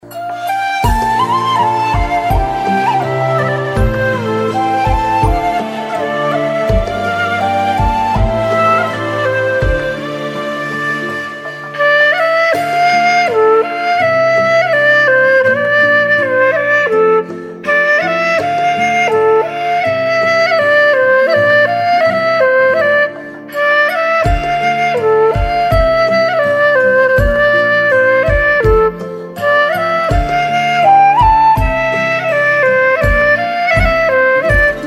背景音乐
笛子版